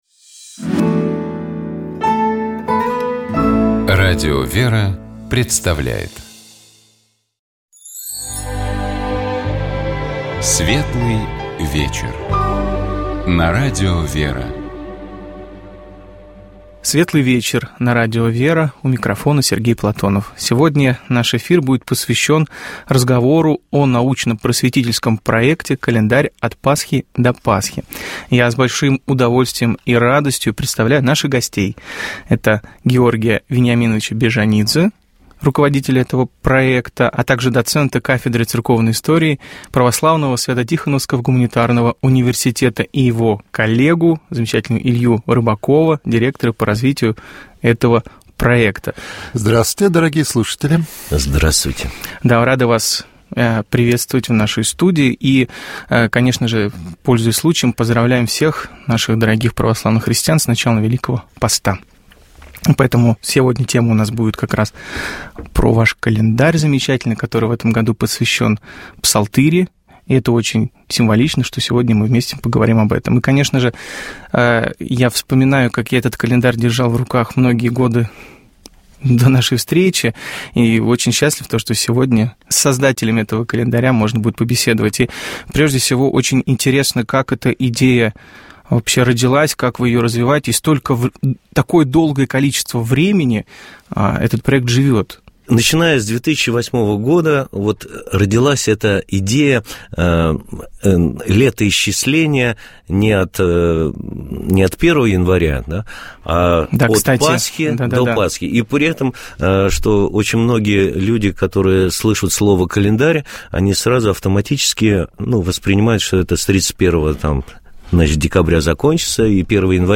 Беседа из цикла про образование, который Радио ВЕРА организует совместно с образовательным проектом «Клевер Лаборатория», которая объединяет учителей, руководителей школ и детских садов, родителей и всех тех, кто работает с детьми и занимается их духовно-нравственным развитием.